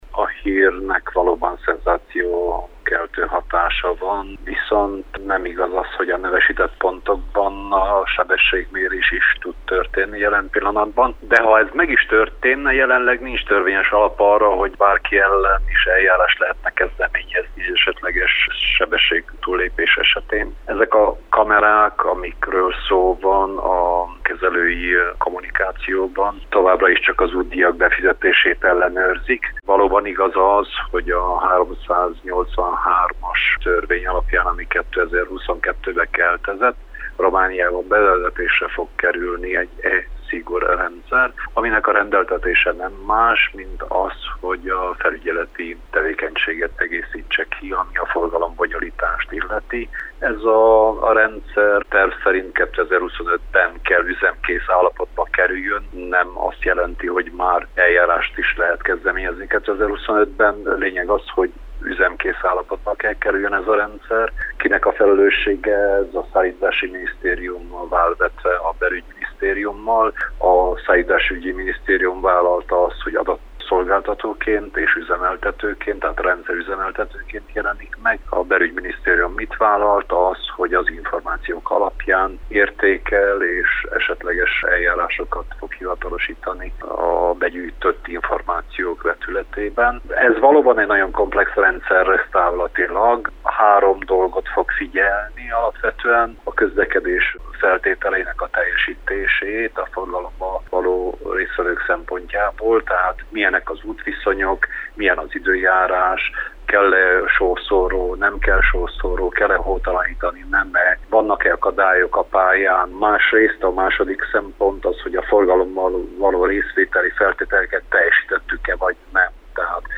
Az intézkedésről Sándor Gábor közlekedésbiztonsági szakértőt, volt közlekedési államtitkárt kérdeztük.